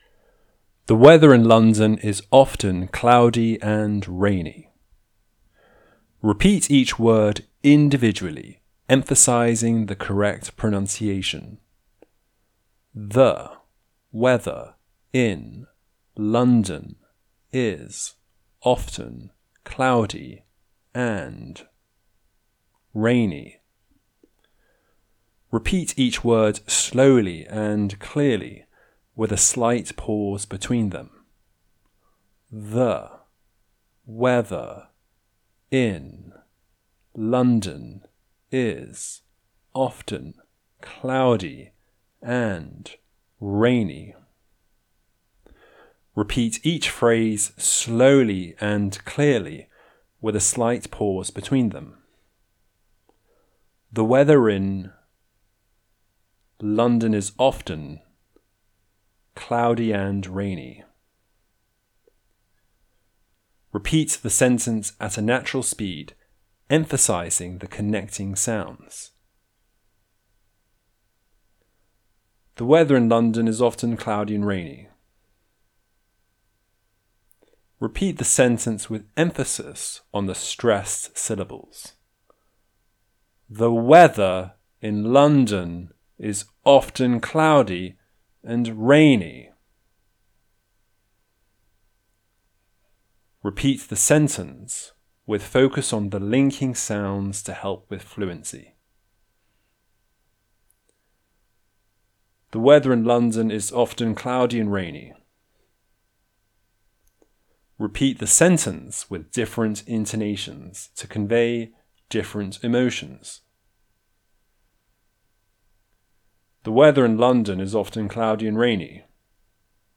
Shadowing Exercises for Improved Fluency - British English Pronunciation RP Online Courses
Repeat each word individually, emphasizing the correct pronunciation: The / weather / in / London / is / often / cloudy / and / rainy.
Repeat each word slowly and clearly with a slight pause between them: